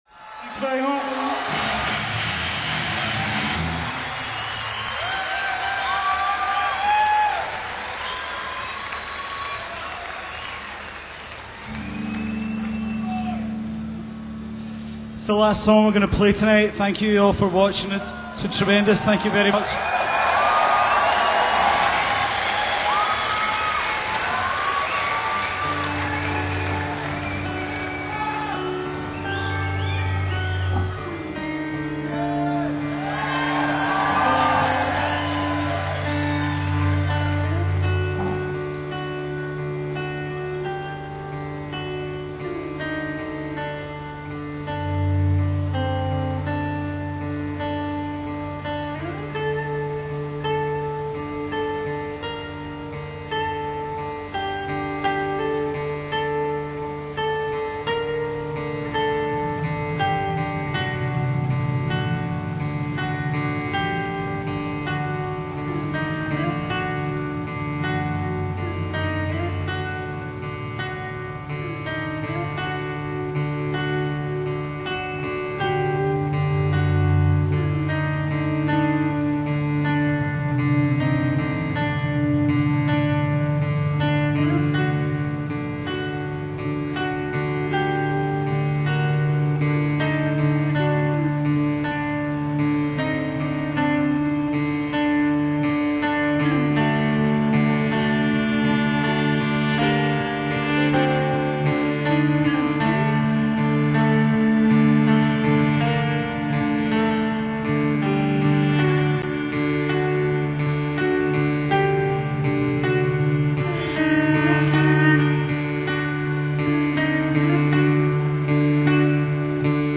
Post-rock